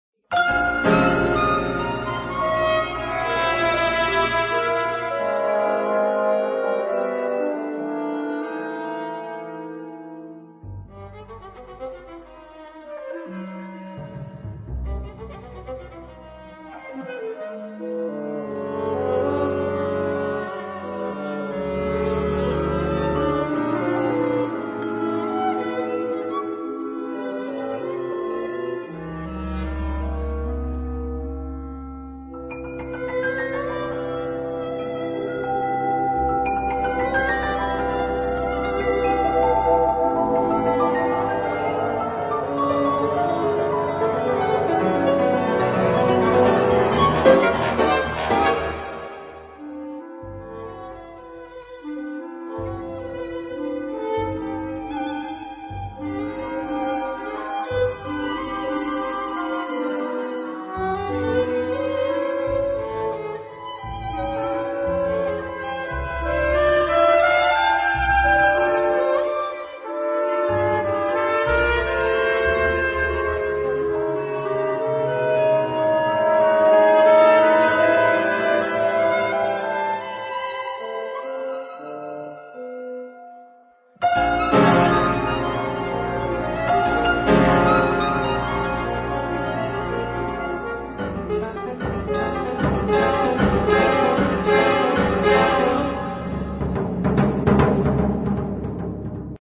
Allegretto Andante (rubato e recitando)